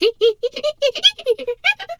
pgs/Assets/Audio/Animal_Impersonations/hyena_laugh_short_05.wav at master
hyena_laugh_short_05.wav